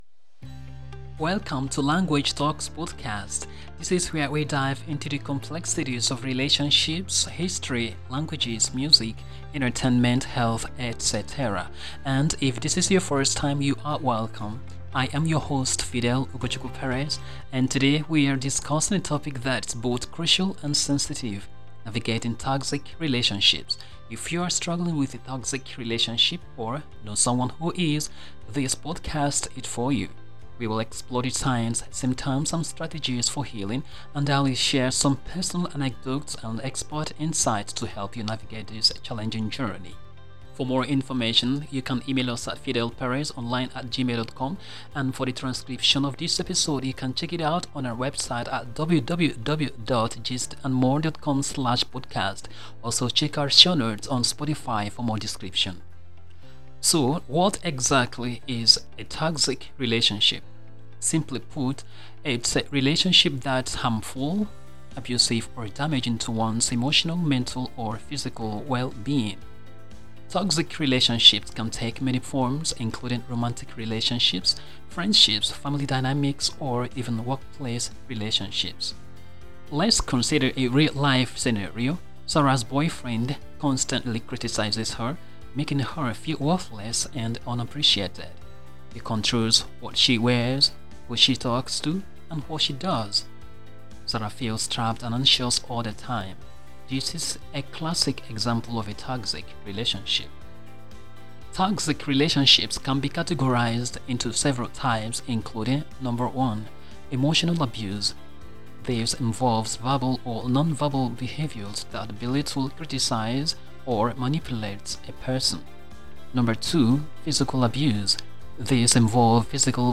[Outro Music ]